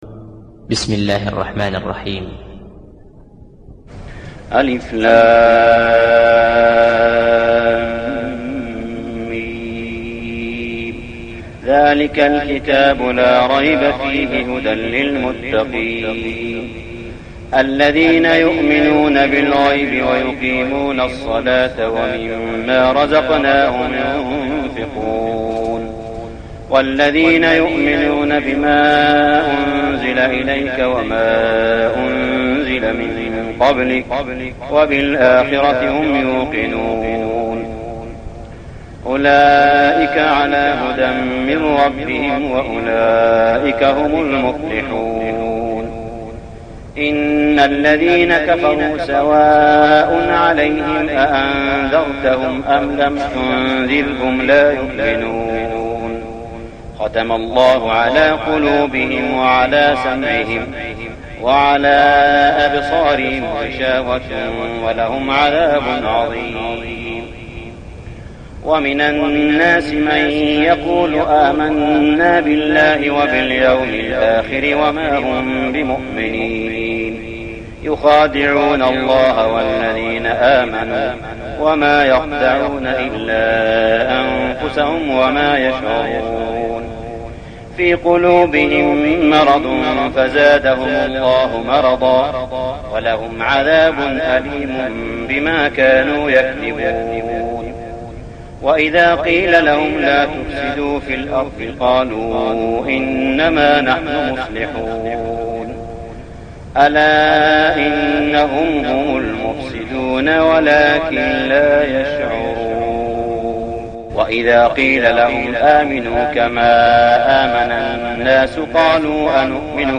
صلاة التراويح ليلة 2-9-1412هـ سورة البقرة 1-73 | Tarawih prayer Surah Al-Baqarah > تراويح الحرم المكي عام 1412 🕋 > التراويح - تلاوات الحرمين